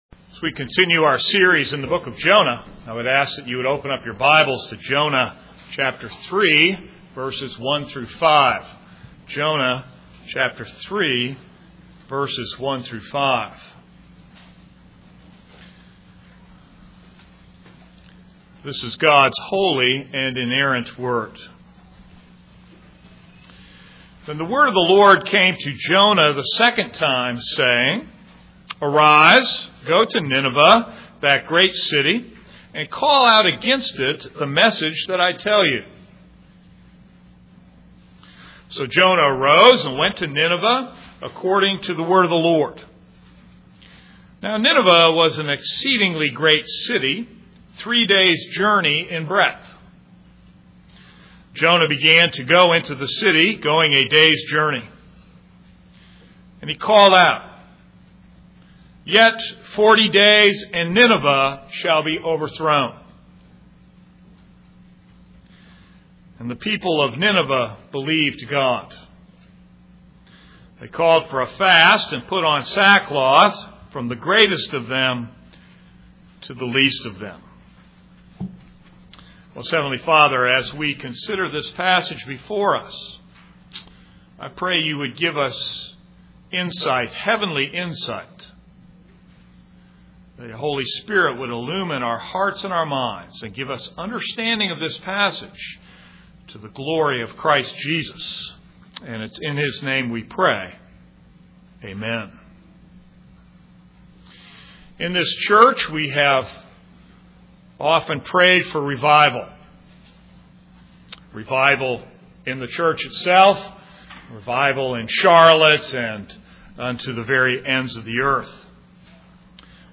This is a sermon on Jonah 3:1-5.